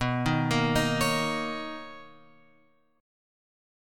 B Major 9th